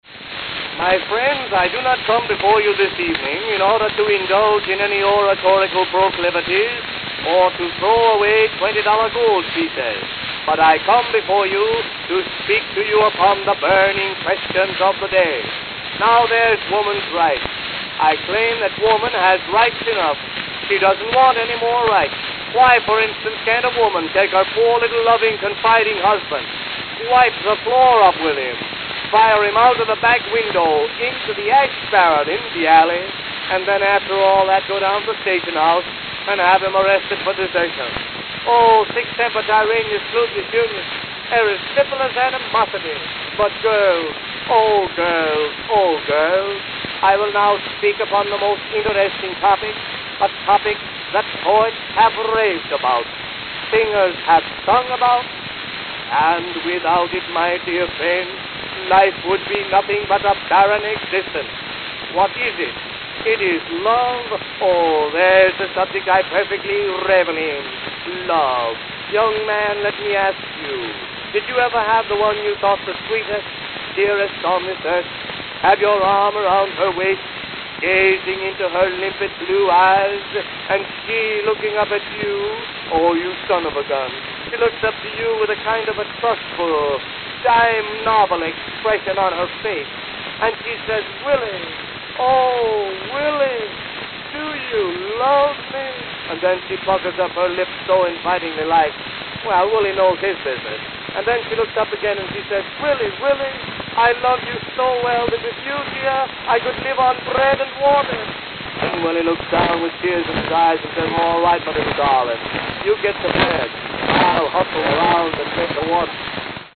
Stump Speech on Love
New York, New York
Note: Worn, surface discoloration, gouged grooves at end.